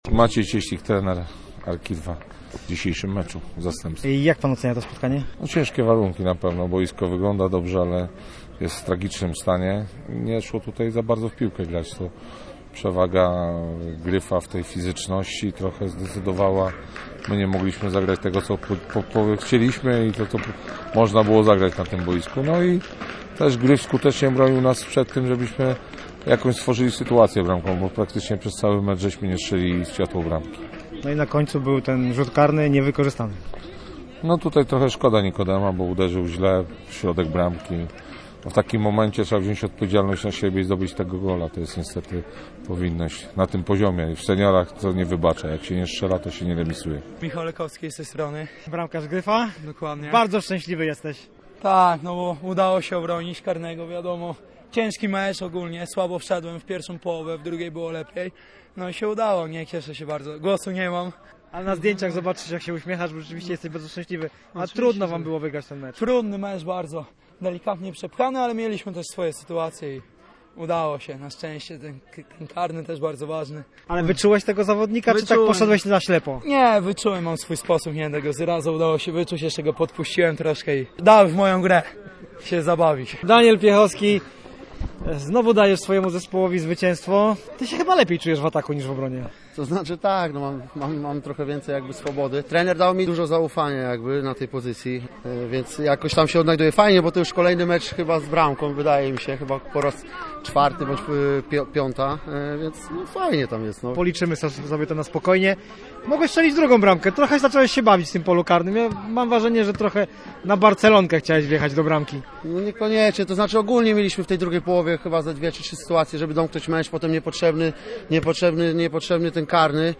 Posłuchaj pomeczowych wypowiedzi: https